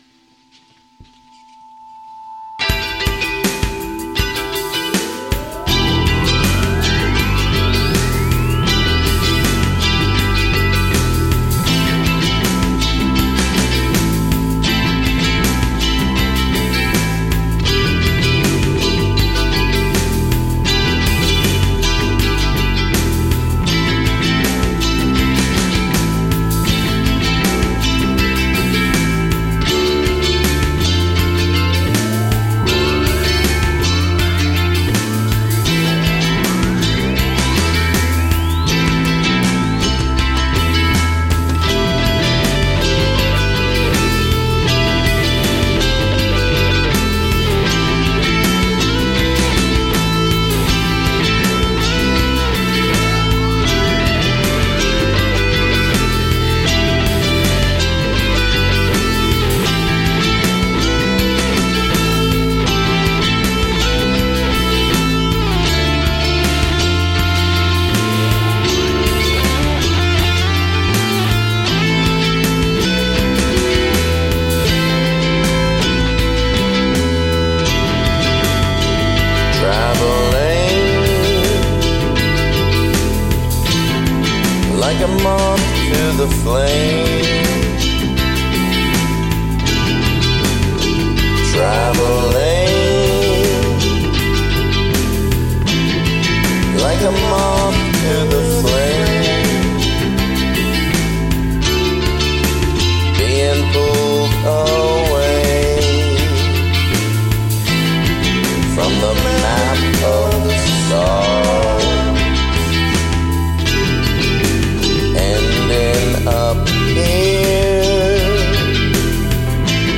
It clocks in around 10:30 so it's an investment but I'm particularly proud of this one. I've been listening to alot of records (yes vinyl) and have been into The Cure lately so this is definitely inspired by them.
All done on the BR80 mostly in the car. 1 rhythm Uke/delayed 1 rhythm Uke/chorus 1 drum-GB (stereo) 1 u-bass 1st lead guitar(stereo) 2nd lead guitar(stereo)
1 theremin/ThumbJam 1 lead vocal 2nd vocal